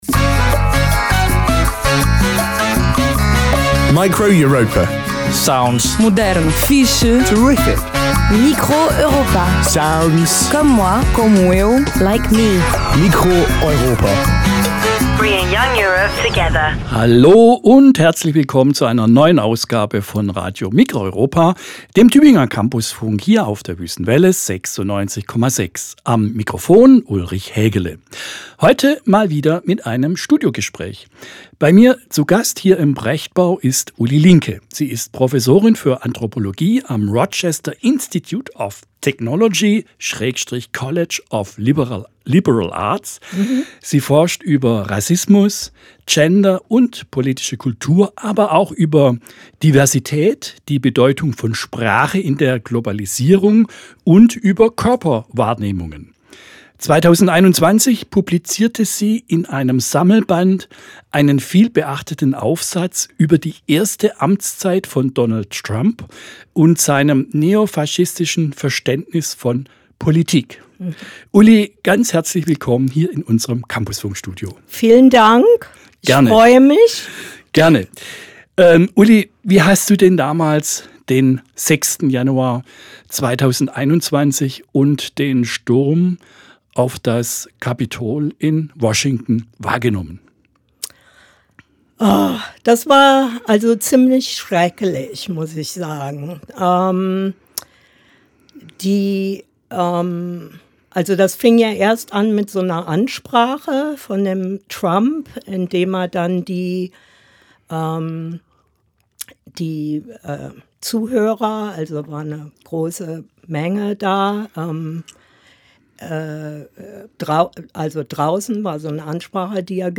Studiogespräch
Form: Live-Aufzeichnung, geschnitten